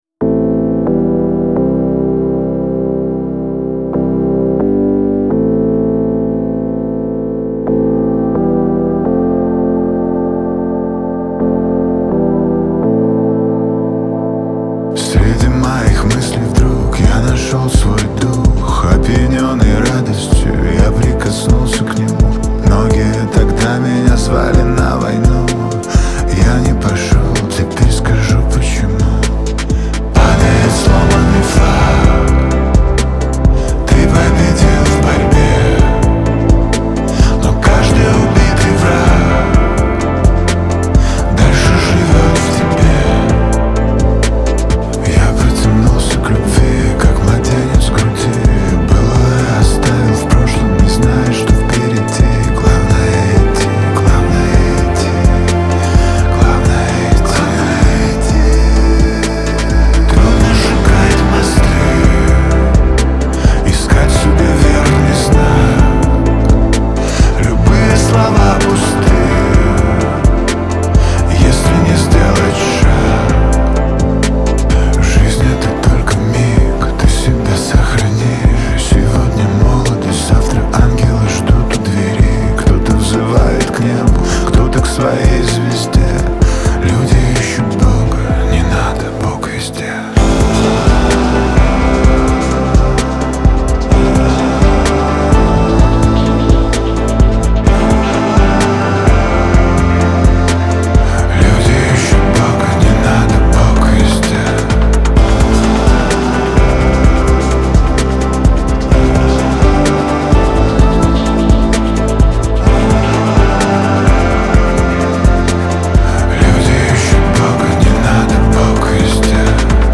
а также поклонникам альтернативного рока.